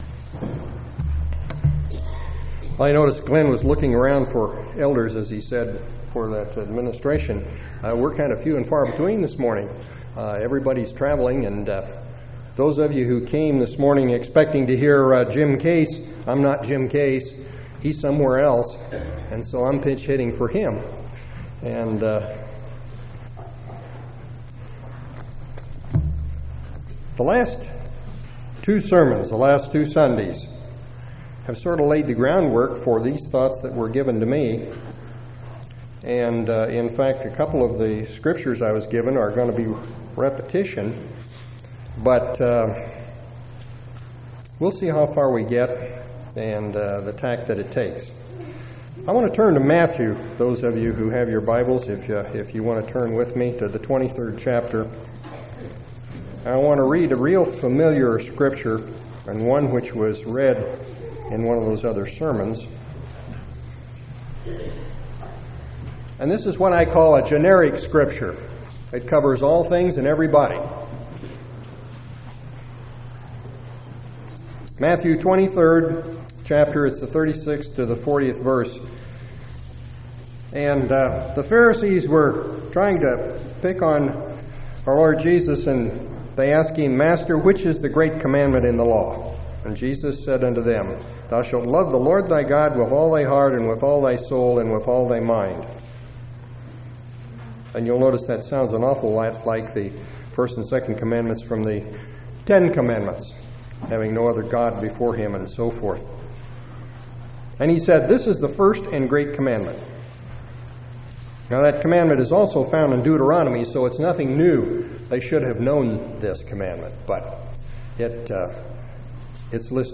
2/18/2001 Location: Temple Lot Local Event